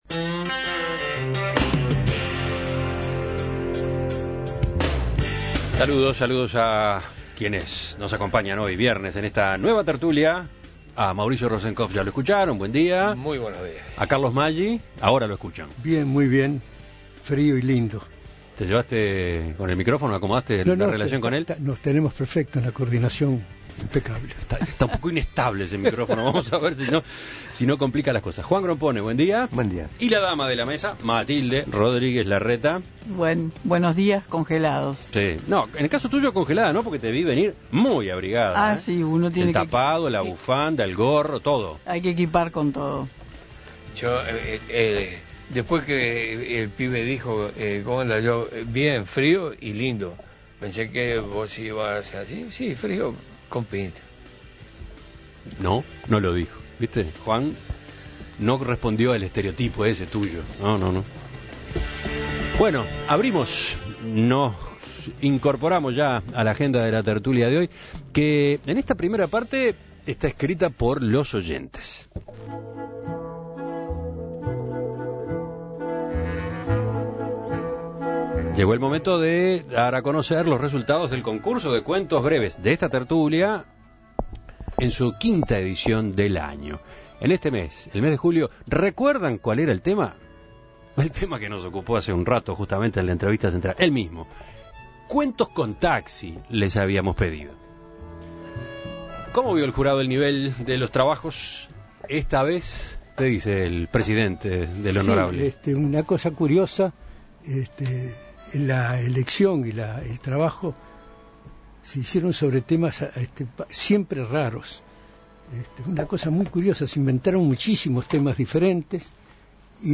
Cuentos leídos por los contertulios en esta quinta edición del concurso de cuentos breves